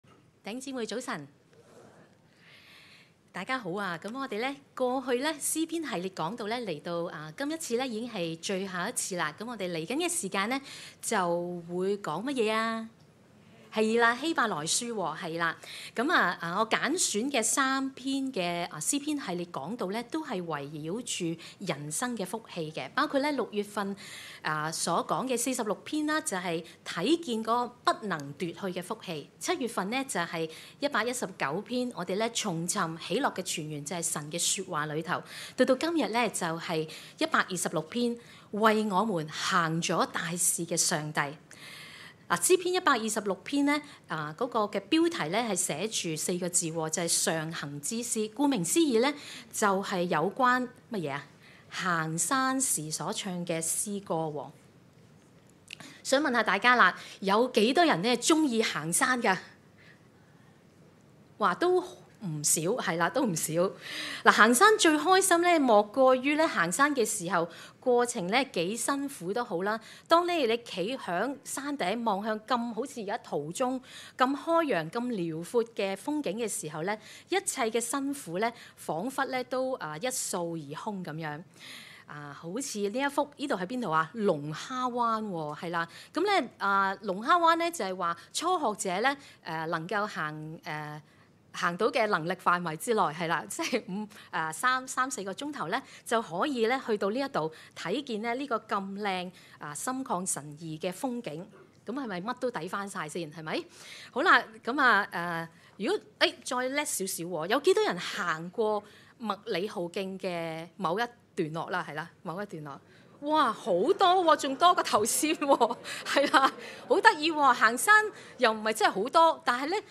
證道集